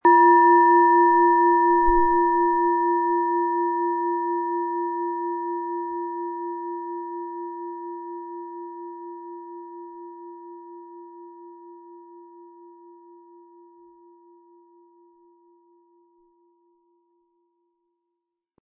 Planetenton 1
Planetenschale® Schwebend und leicht fühlen & Lichtvoll sein mit Chiron, Ø 10,2 cm inkl. Klöppel
Um den Originalton der Schale anzuhören, gehen Sie bitte zu unserer Klangaufnahme unter dem Produktbild.
Der gratis Klöppel lässt die Schale wohltuend erklingen.